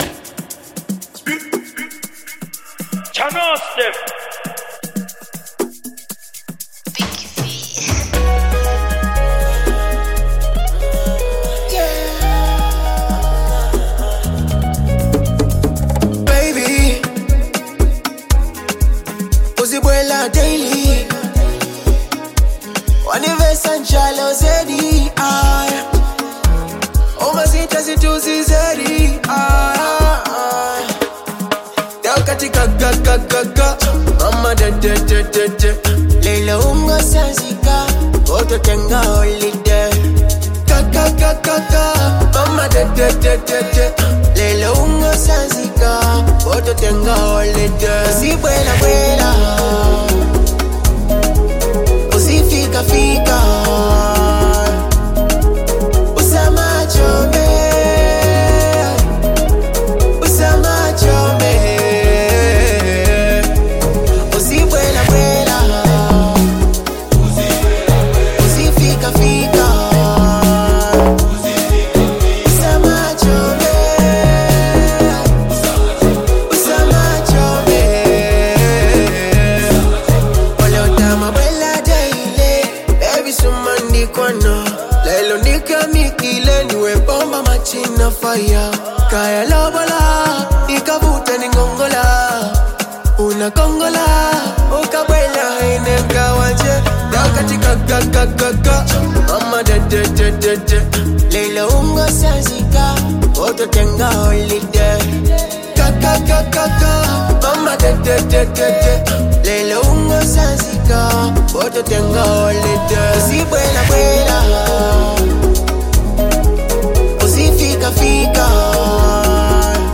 Genre : Afro Beat
dynamic vocals and engaging delivery
upbeat instrumentals, catchy hooks, and danceable beats